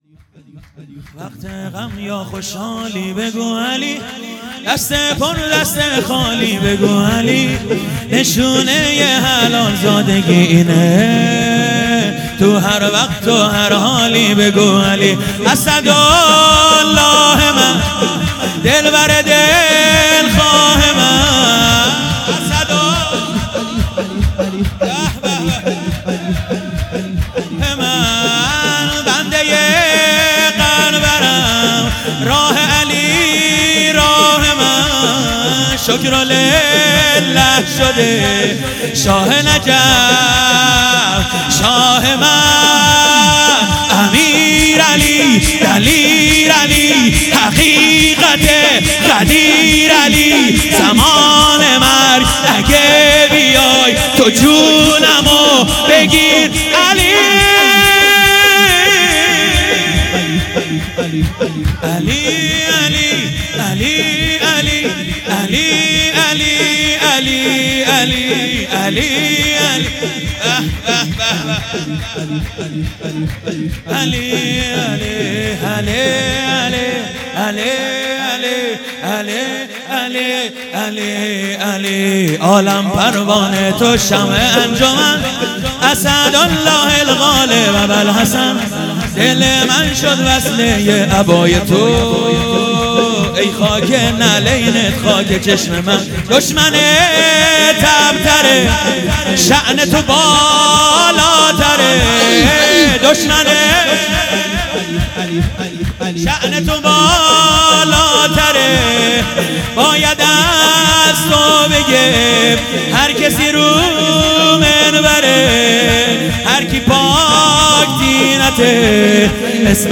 شب دوم جشن میلاد حضرت زهرا سلام الله علیها